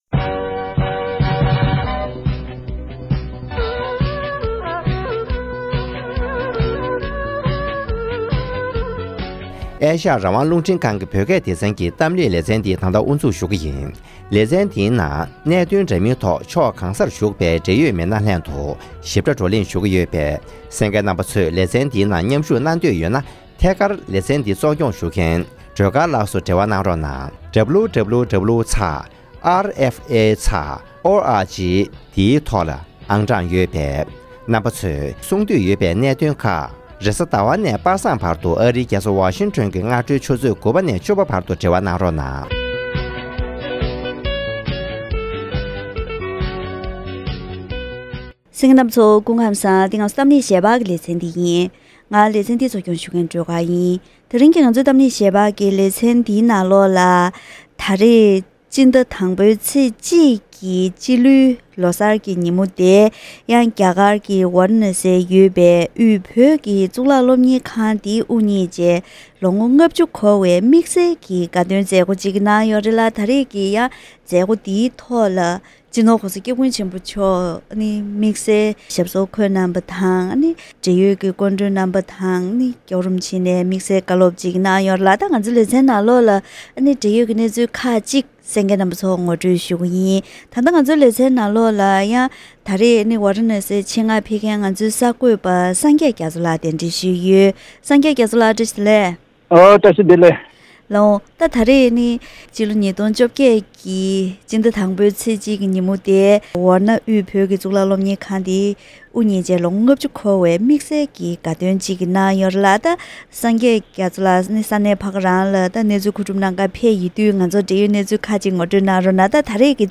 དབུས་བོད་ཀྱི་གཙུག་ལག་སློབ་གཉེར་ཁང་དབུ་བརྙེས་ནས་ལོ་ངོ་༥༠འཁོར་བའི་མཛད་སྒོར་༧གོང་ས་མཆོག་ནས་བཀའ་སློབ་གནང་བ།